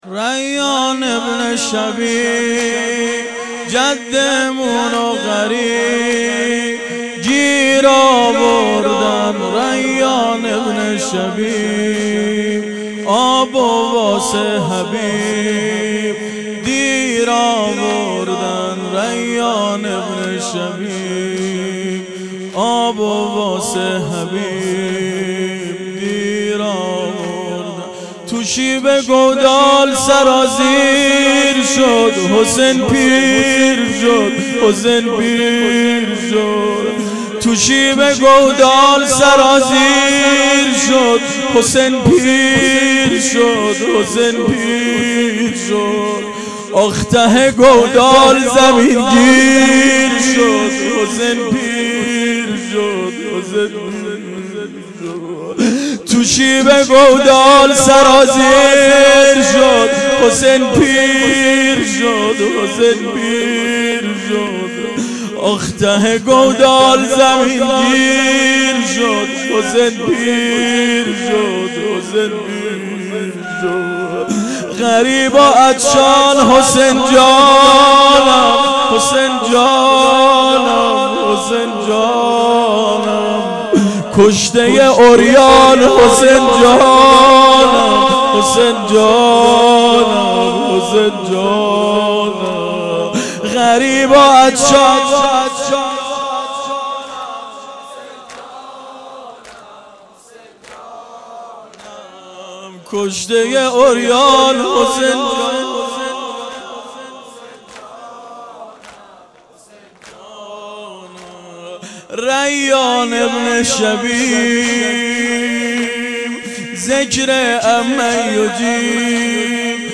زمینه شب یازدهم محرم 98